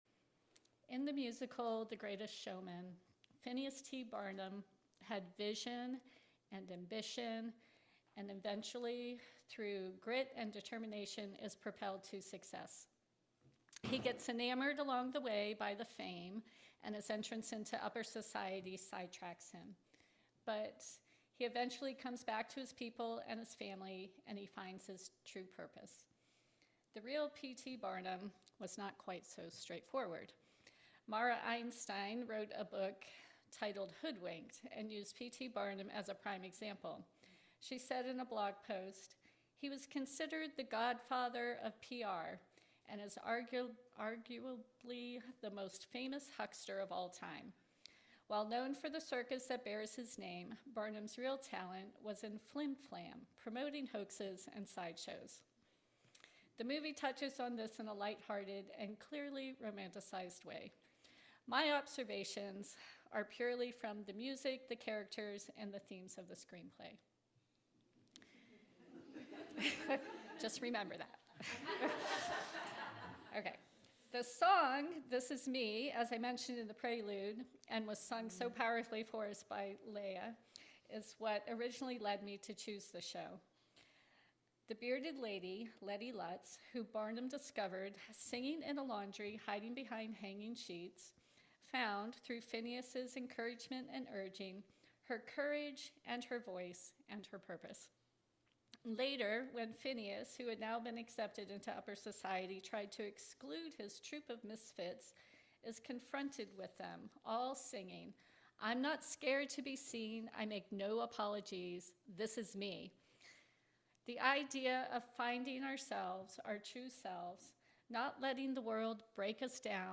This sermon uses the narrative of The Greatest Showman and the life of P.T. Barnum as a springboard to examine the human struggle between the true self and the false persona.